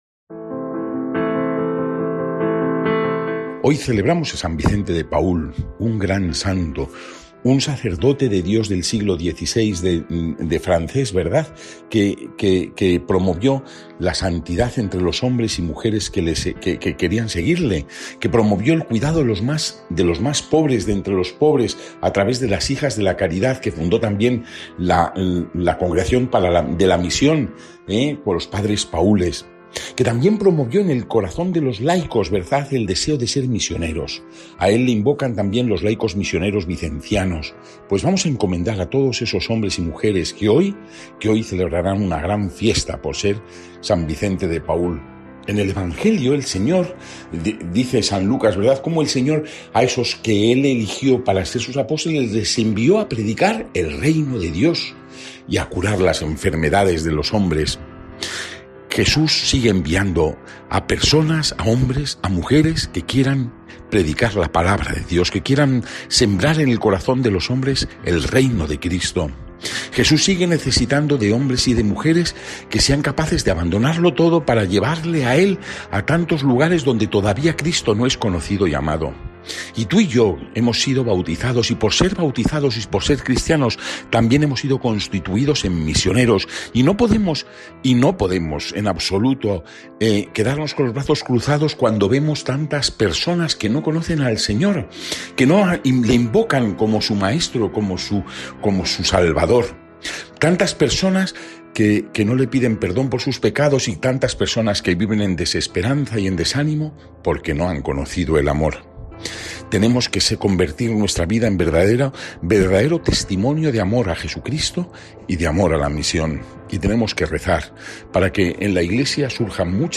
Lectura del santo evangelio según san Lucas (9,1-6)